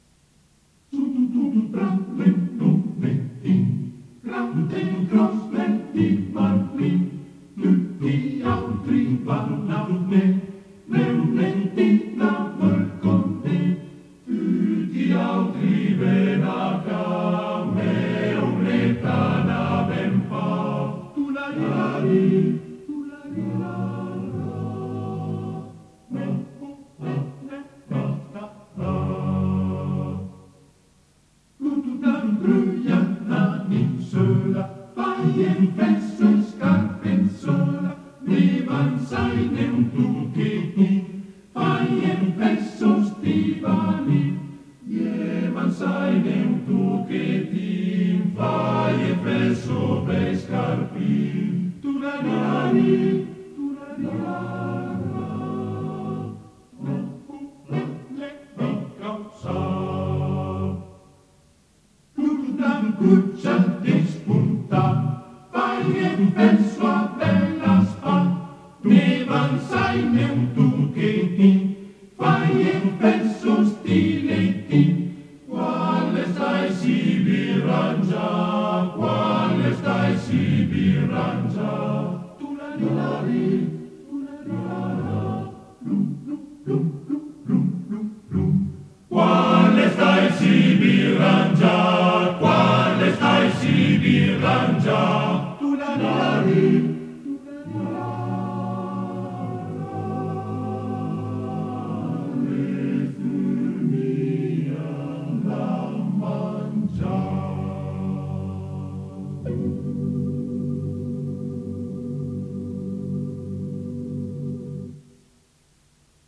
Esecutore: Coro della SAT